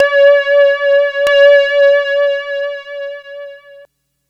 Analog Drone 01.wav